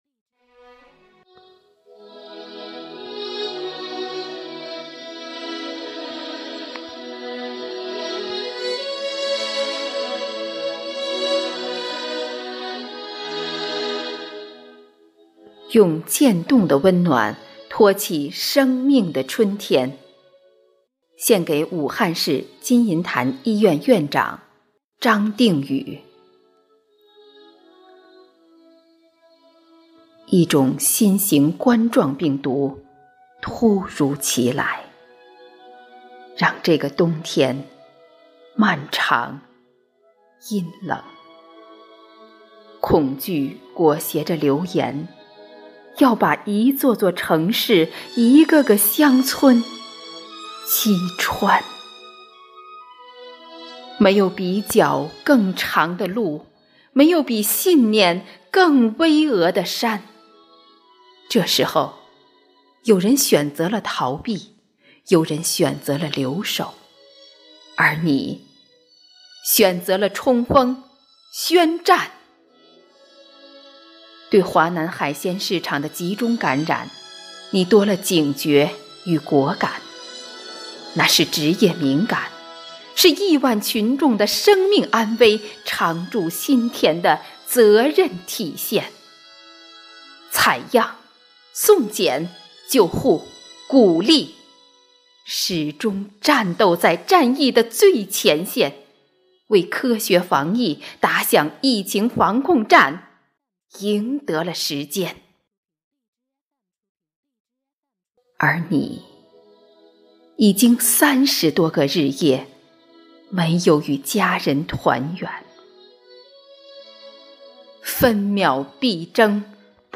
为声援战斗在一线的工作人员，鼓舞全区人民抗击疫情的信心和决心，丰南文化馆、百花艺术团、丰南诗歌与朗诵协会组织诗歌与诵读工作者、爱好者共同创作录制诵读作品。